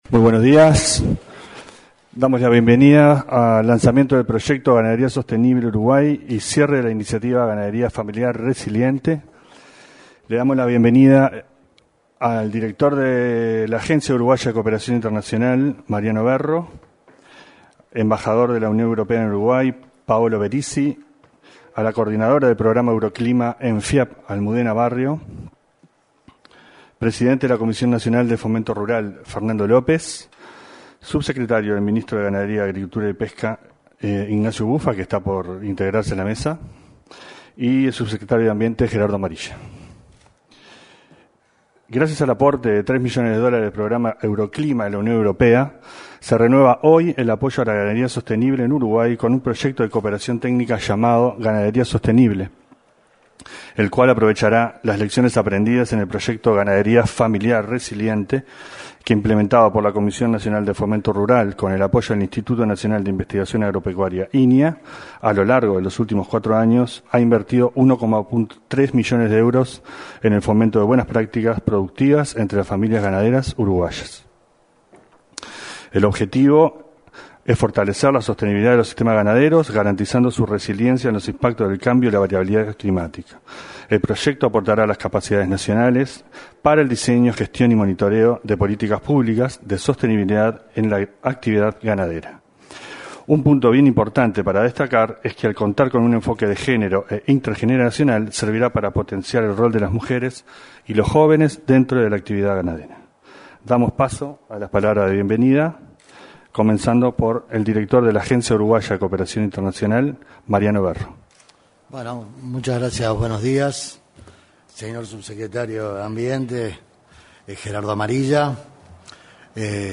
Lanzamiento de proyecto Ganadería Sostenible Uruguay y cierre de Ganadería Familiar Resiliente 17/09/2024 Compartir Facebook X Copiar enlace WhatsApp LinkedIn Este martes 17, en el salón de actos de la Torre Ejecutiva, se realizó el lanzamiento del proyecto Ganadería Sostenible y el cierre del proyecto Ganadería Familiar Resiliente.